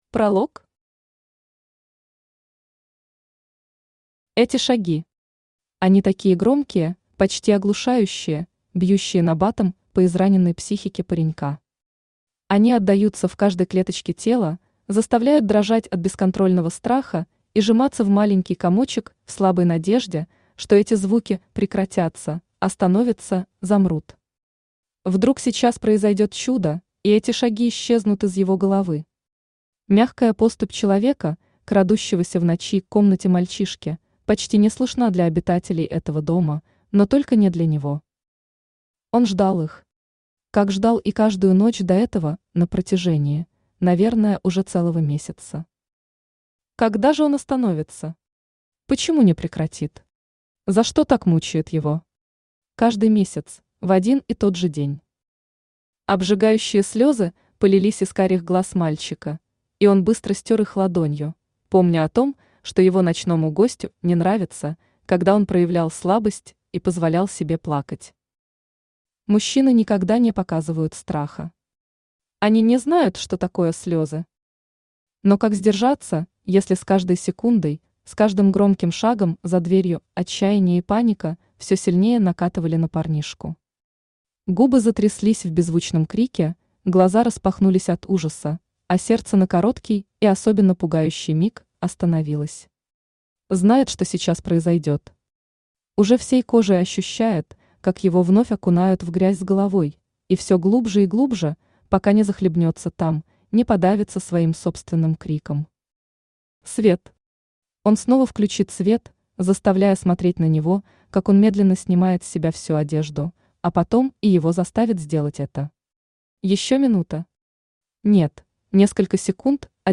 Аудиокнига Отражение в зеркале | Библиотека аудиокниг
Aудиокнига Отражение в зеркале Автор Анастасия Александровна Дюльдина Читает аудиокнигу Авточтец ЛитРес.